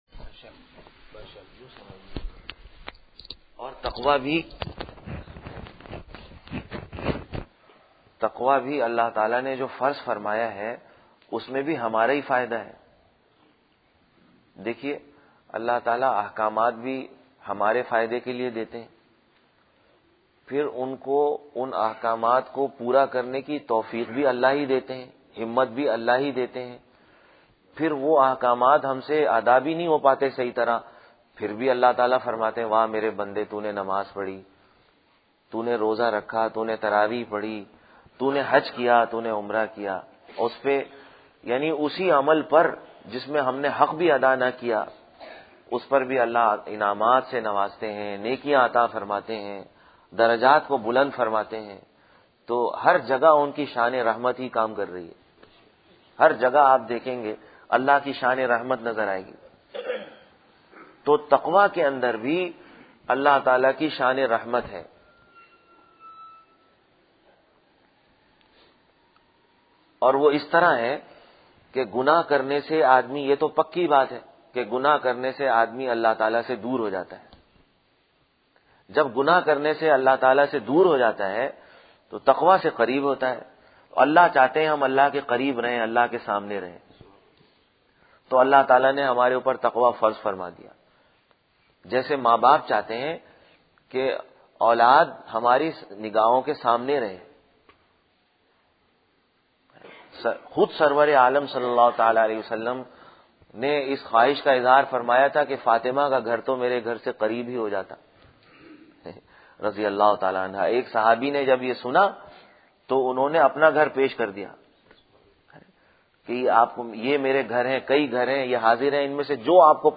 16-Jan-2015 After Fajr Bayan ( Noorani Masjid)